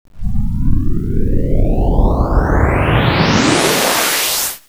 alien woosh 01.wav